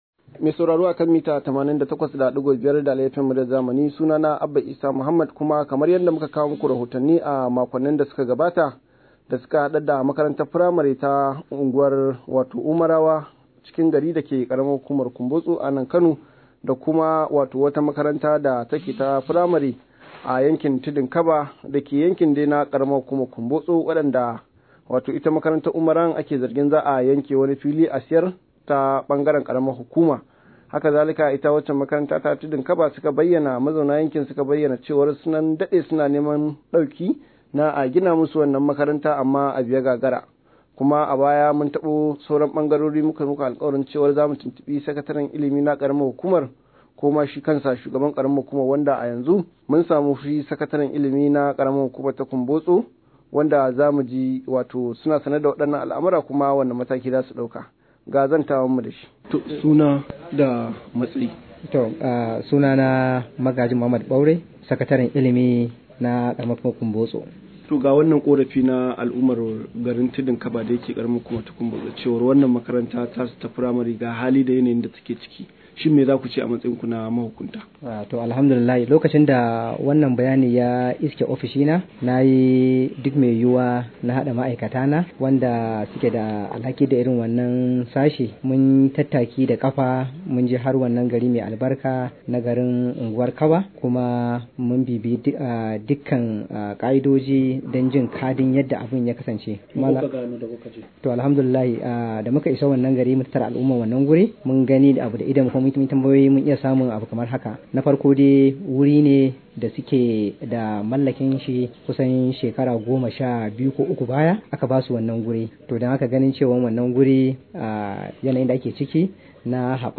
Rahoto: Za mu duba yadda za a samarwa makarantar Tudun Kaba mafita – Sakataren ilimi